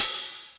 kanye hats_29-06.wav